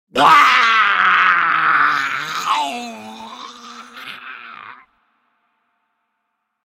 Zombie Moan Sound Effect
A terrifying and realistic undead groan perfect for horror movies, zombie games, Halloween projects, and scary video scenes. Ideal for adding fear and tension to any spooky atmosphere.
Zombie-moan-sound-effect.mp3